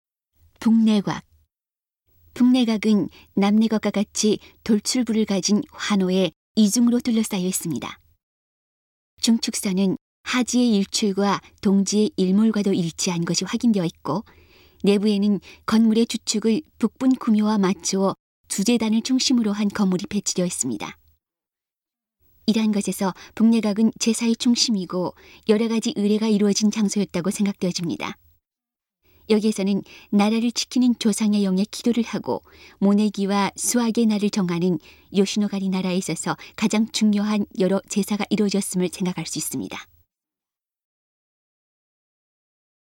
음성 가이드 이전 페이지 다음 페이지 휴대전화 가이드 처음으로 (C)YOSHINOGARI HISTORICAL PARK